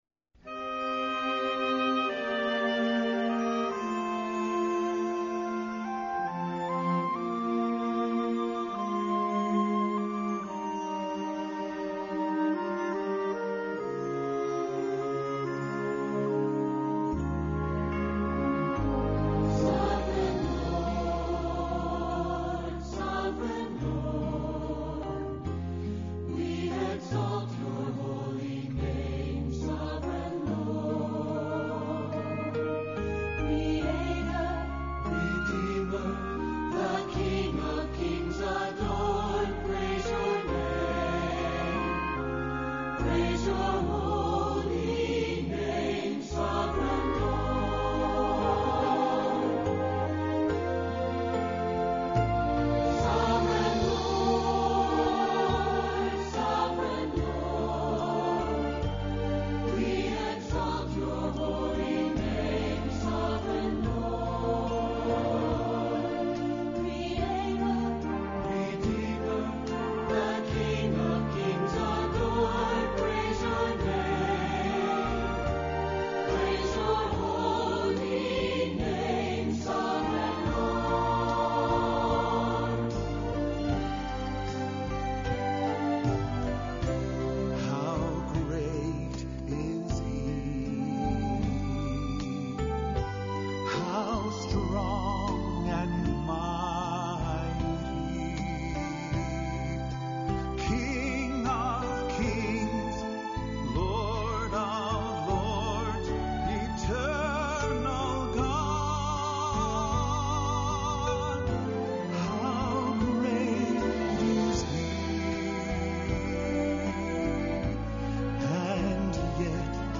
This week’s Devotional Topic This week we will be singing and talking about the Promises of God for our lives.